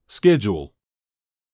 読み上げた単語は”schedule（スケジュール）”、①がイギリス英語、②がアメリカ英語です。
schedule/ˈʃedʒ.uːl//ˈskedʒ.uːl/
②AE-Schedule.mp3